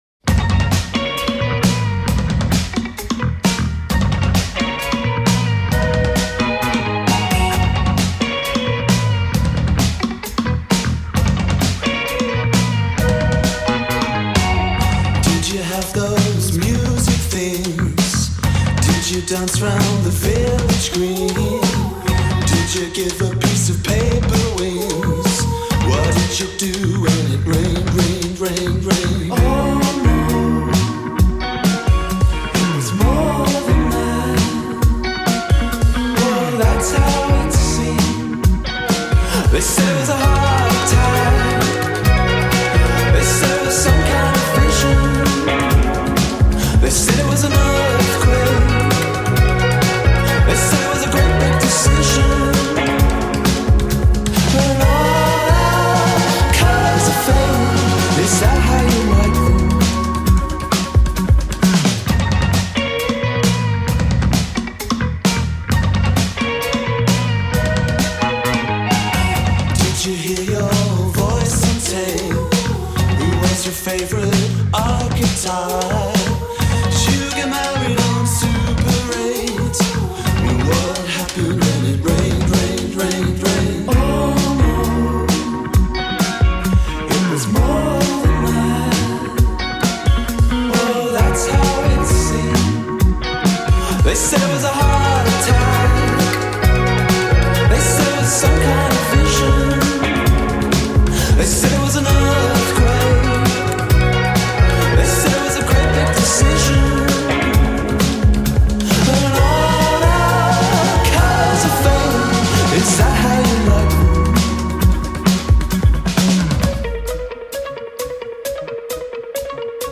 Indiepop, indie rock e brindisi!